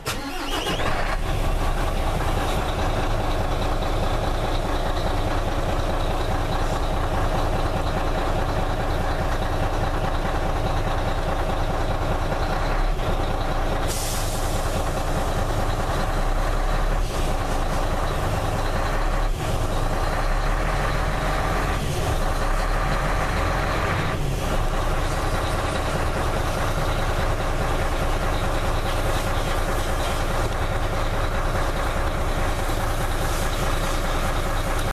Coldstart_Truck_Merc..> 2023-03-29 08:47  1.3M